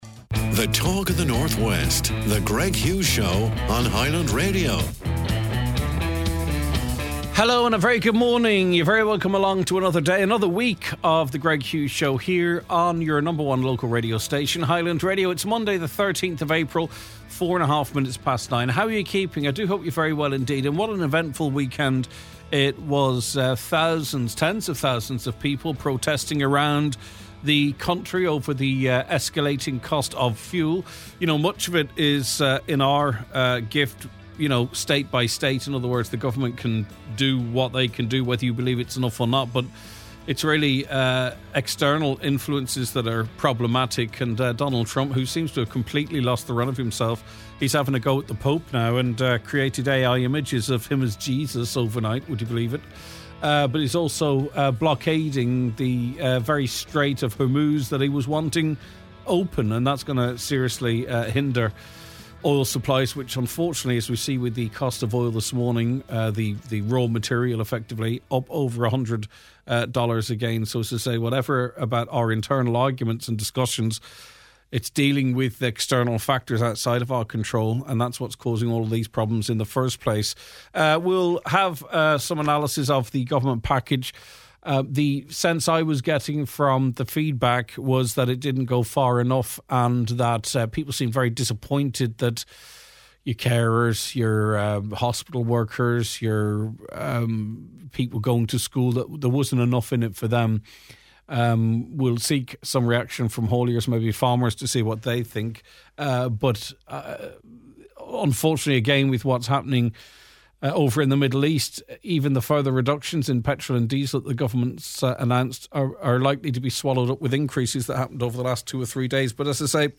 The Political Divide Following our morning look at the headlines, we engage in a heated debate over the State’s response to the cost-of-living crisis: Senator Niall Blaney (Fianna Fáil): Defends the Government’s package as a balanced measure in volatile times.
While both express deep sympathy for the public’s plight, they confirm they will be voting confidence in the Government this week. A Musical Interlude To close out the show on a high note